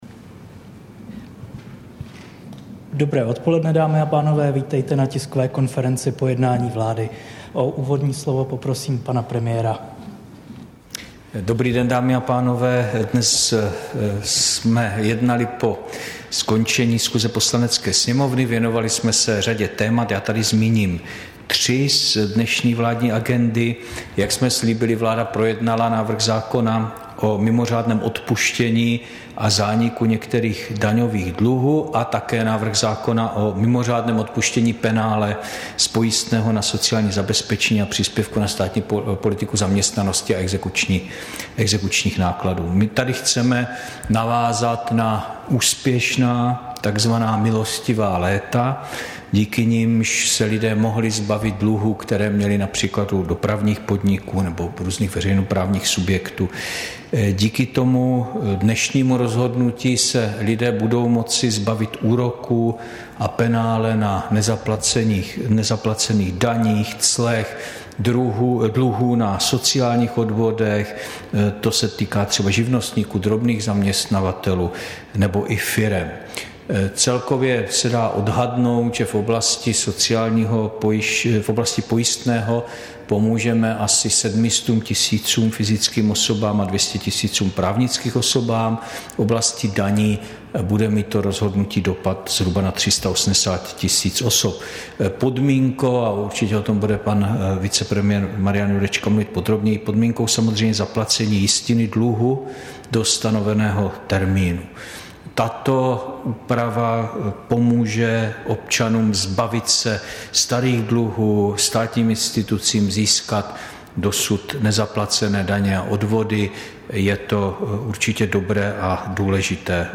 Tisková konference po jednání vlády, 25. ledna 2023